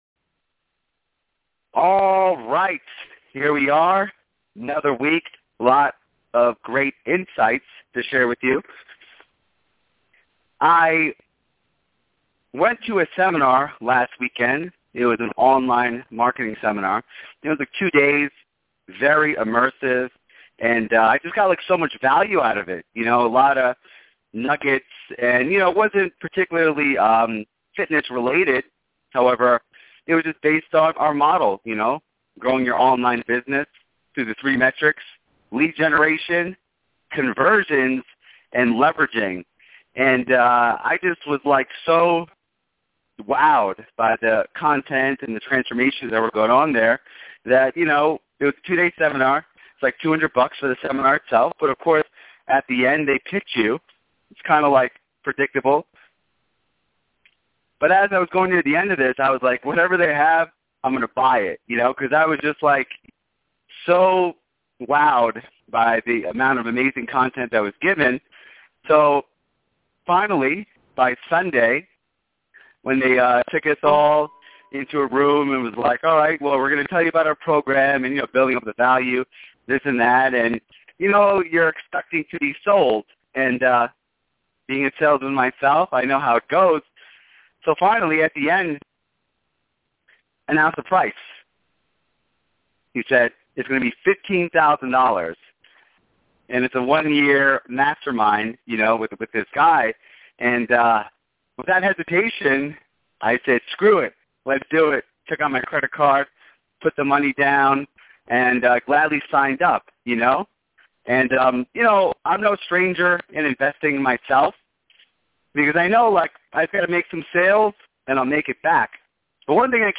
Listen to the workshop then download the 10 Minute Call Script.